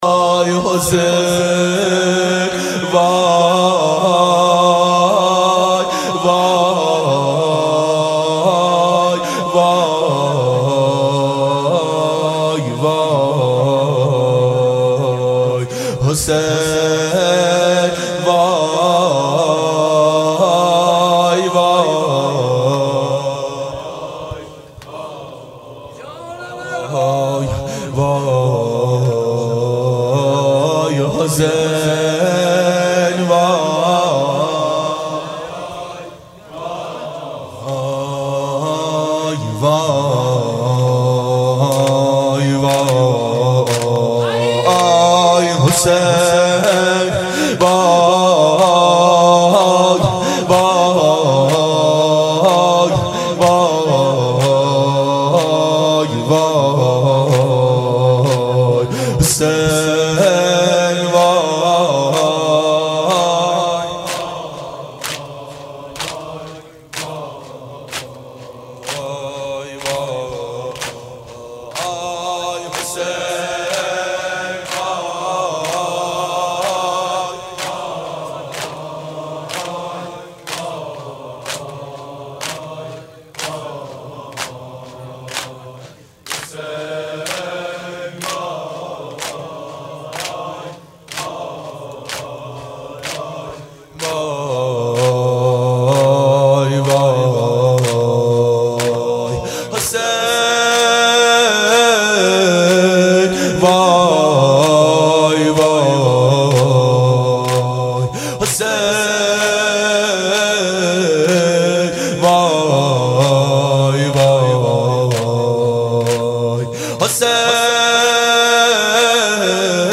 دهه اول صفر سال 1390 هیئت شیفتگان حضرت رقیه س شب سوم (شام غریبان)